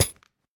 sounds / block / chain / step5.ogg
step5.ogg